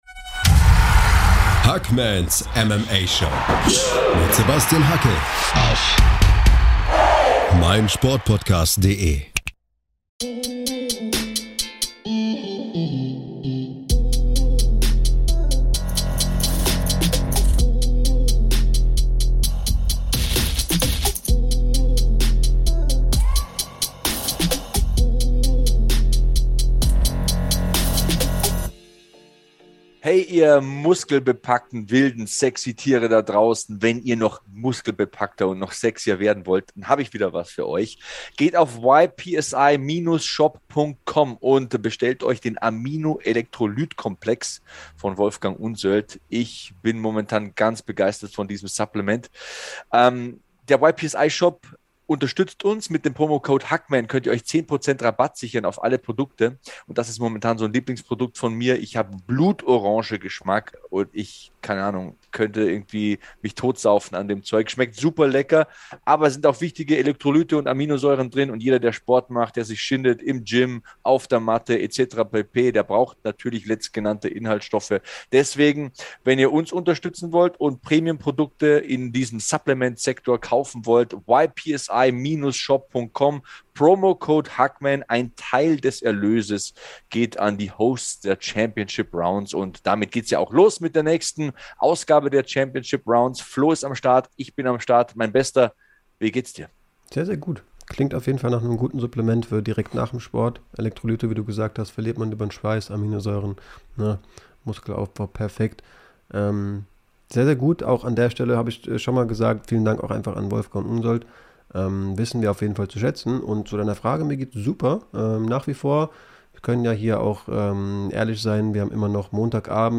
Unser erstes Q&A: Welche Perspektive hat deutsches MMA? Was kann Conor McGregor noch reißen?